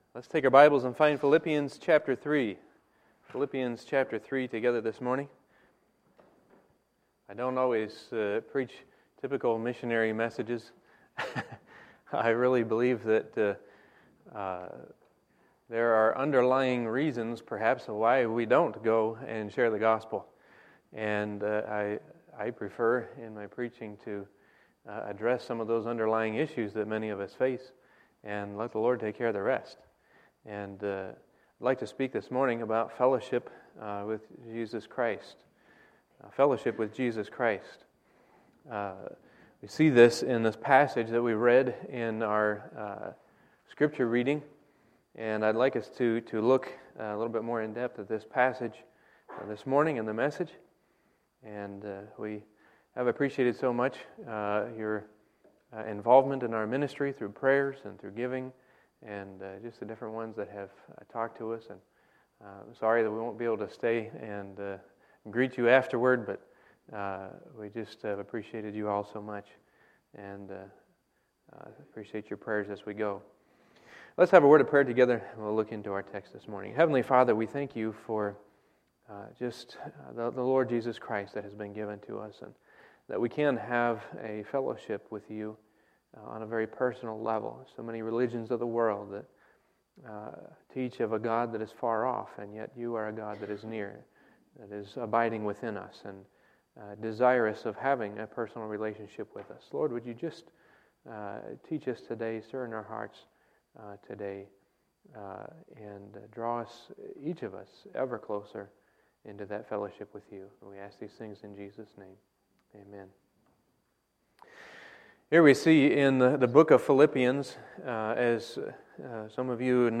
Sunday, August 9, 2015 – Sunday Morning Service